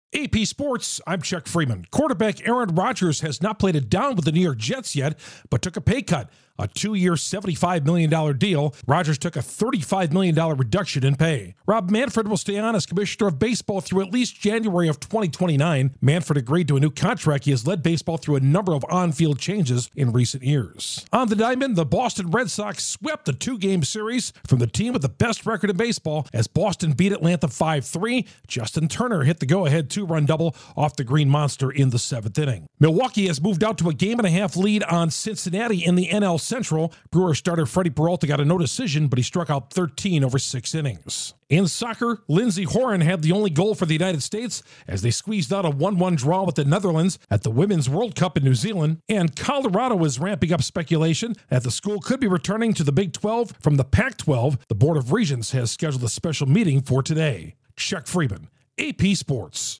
Aaron Rodgers agrees to a new deal with the Jets, Rob Manfred to stay on as MLB Commissioner, the Red Sox sweep the Braves and Colorado could be returning to the Big 12. Correspondent